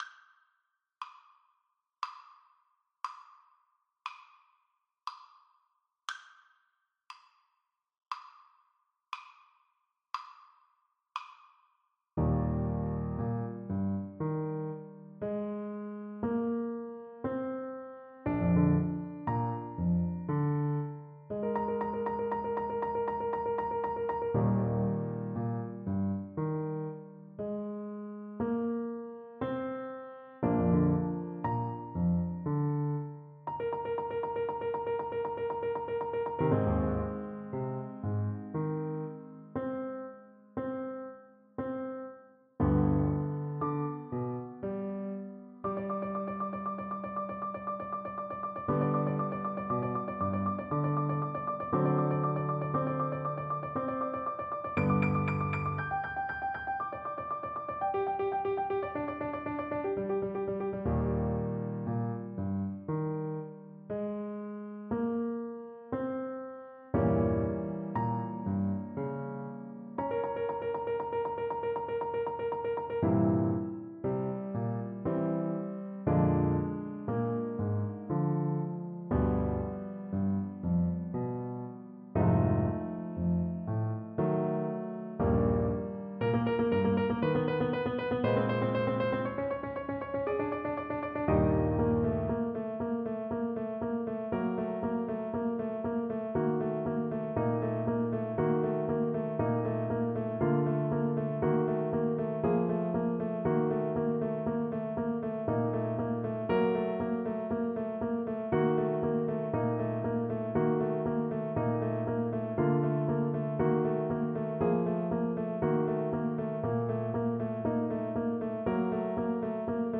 Andantino = 116 (View more music marked Andantino)
6/8 (View more 6/8 Music)
Classical (View more Classical Trumpet Music)